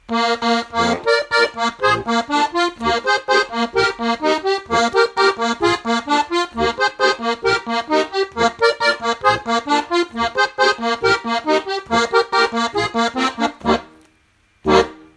Kurzer Ausschnitt des Stücks, an dem ich gerade arbeite - die Lösung sollte vermutlich auch nicht sooo schwierig sein: Anhänge Steirische Ratespiel 3.mp3 211,6 KB